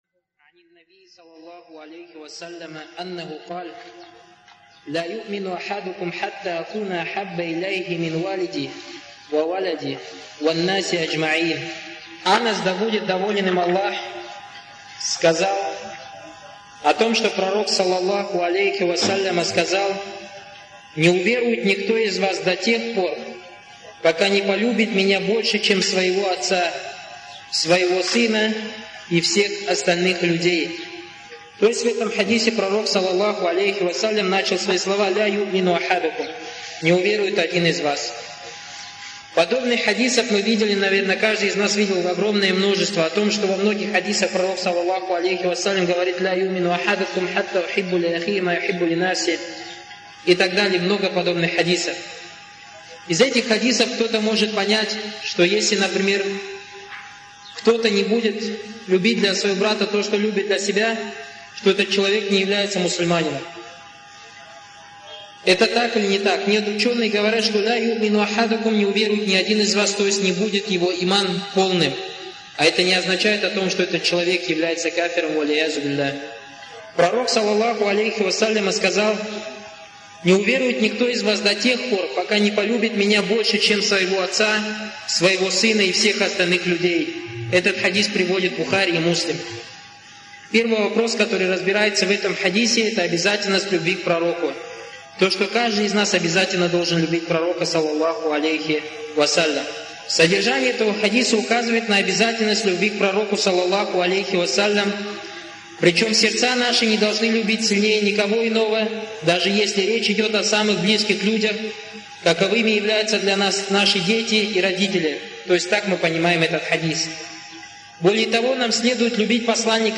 وفي هذه المحاضرة بيان لذلك.